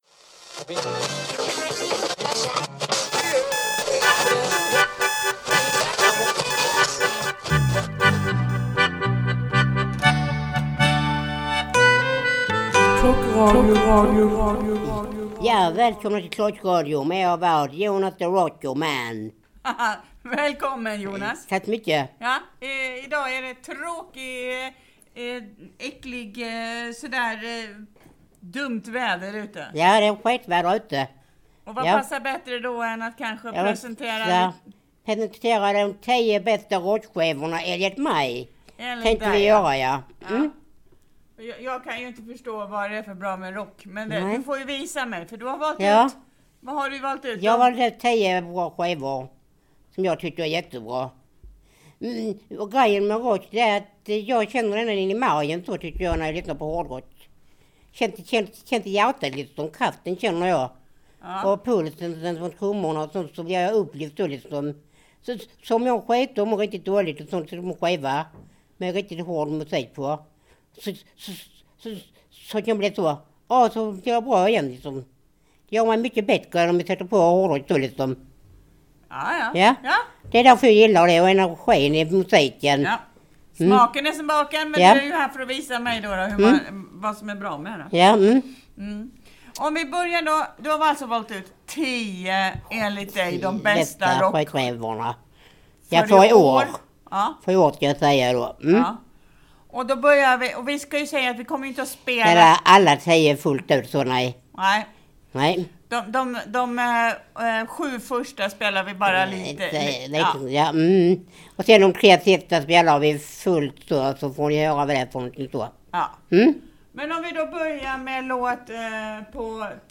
Vi kurar ihop oss i Änglarummet på Barbacka och pratar om vad man göra för att skydda sig mot förkylningar samt hur man gör hösten lite ljusare.
Vi tipsar om sköna skräckfilmer och vi lyssnar på ”skäckig” musik.